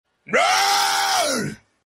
Ignore My Yawn Sound Effect Free Download